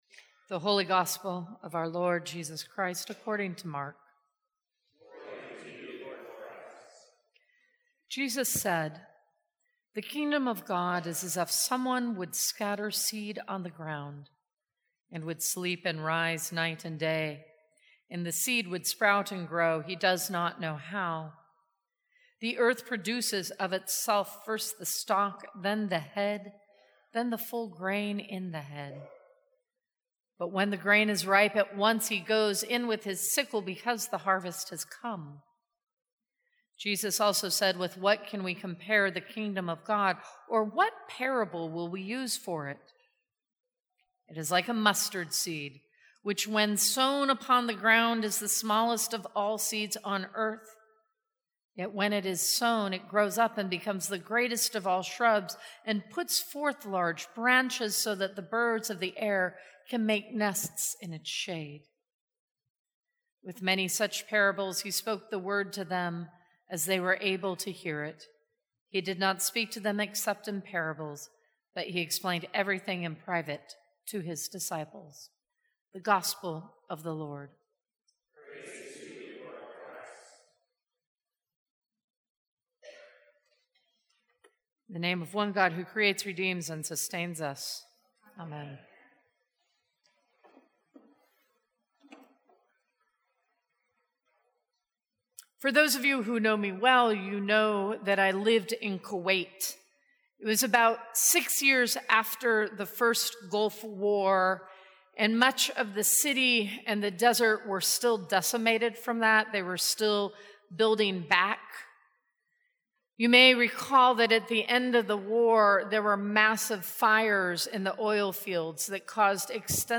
Sermons from St. Cross Episcopal Church Sow the Seed Jun 20 2018 | 00:18:06 1x 00:00 / 00:18:06 Subscribe Share Apple Podcasts Spotify Overcast RSS Feed Share Link Embed